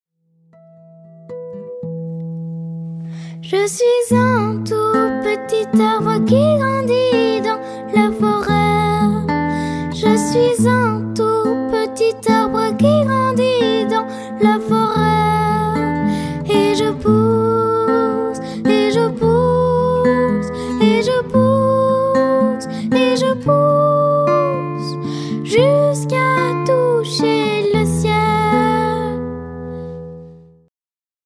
Voici quelques chants pratiqués en atelier.